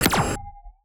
UIClick_Menu Strong Tonal.wav